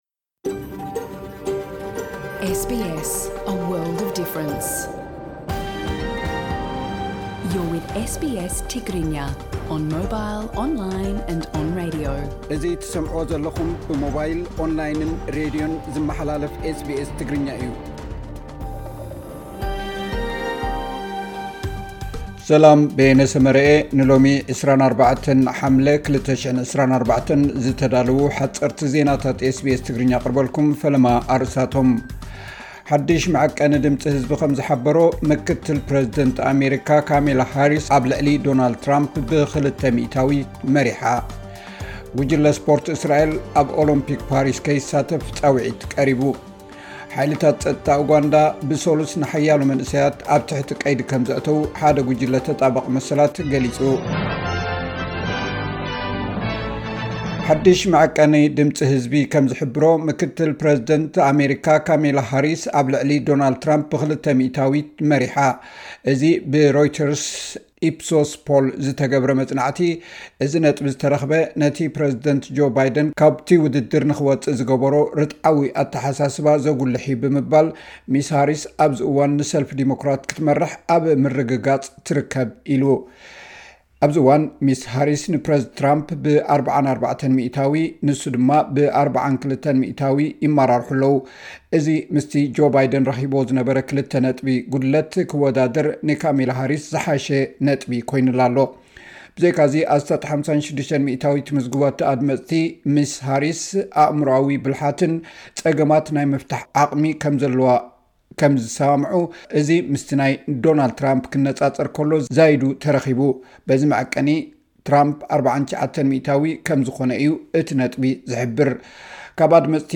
ሓጸርቲ ዜናታት ኤስ ቢ ኤስ ትግርኛ (24 ሓምለ 2024)